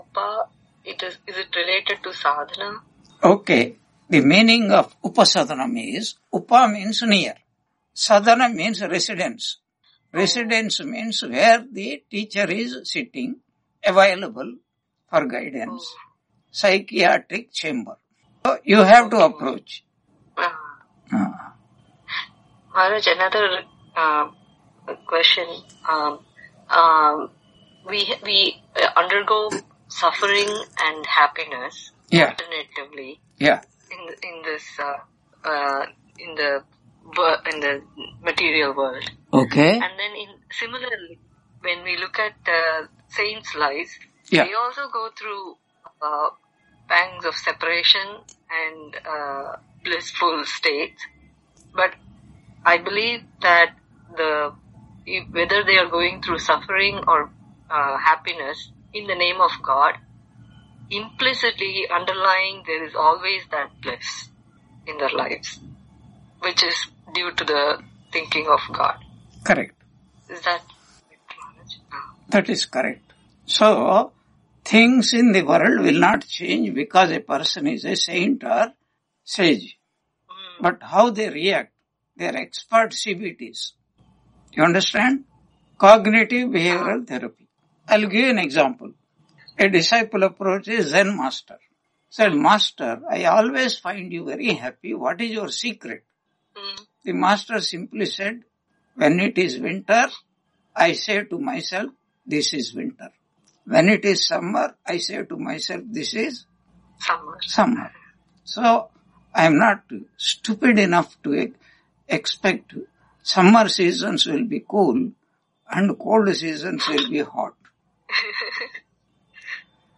Brihadaranyaka Upanishad Introduction Lecture 04 on 01 February 2026 Q&A - Wiki Vedanta